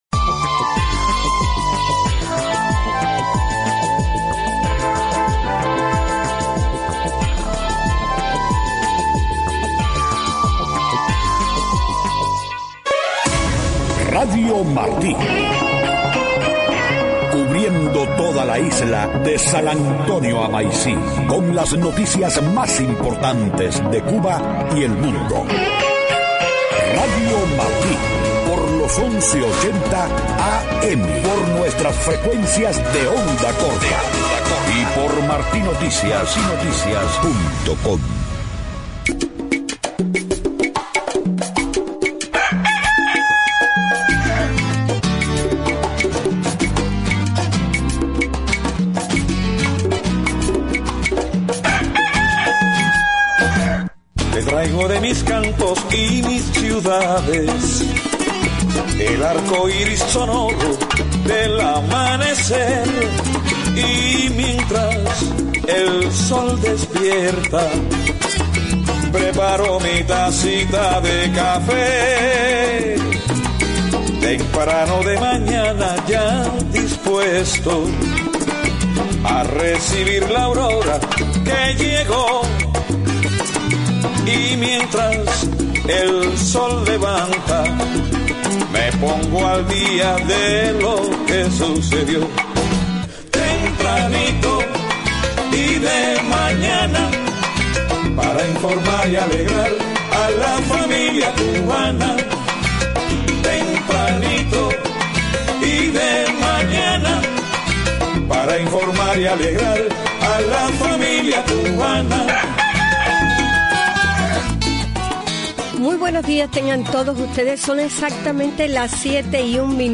7:00 a.m Noticias: Presidente de México, Peña Nieto, ofrece ayudar en proceso de normalización de relaciones entre EEUU y Cuba. UE y Cuba buscan nueva fecha para proseguir ronda de conversaciones interrumpidas en diciembre por La Habana. 8 legisladores hispanos asumen puestos en el nuevo Congreso de EEUU.